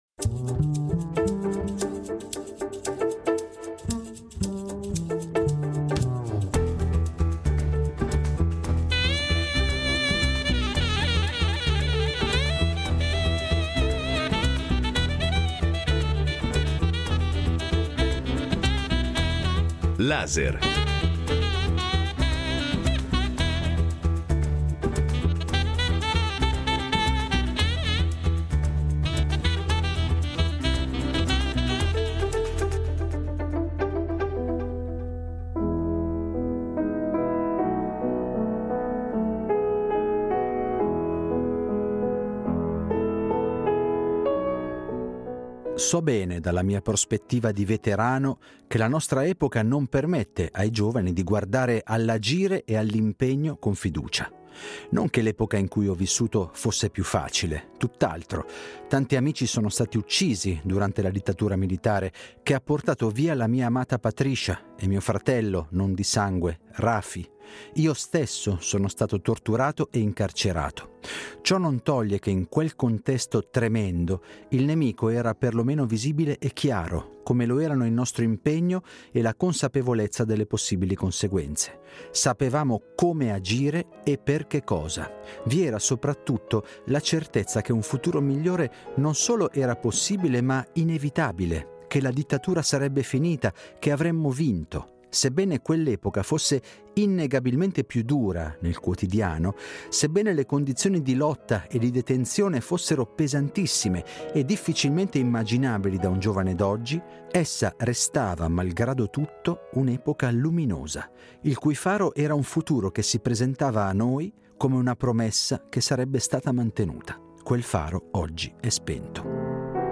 Incontro con il filosofo e psicanalista Miguel Benasayag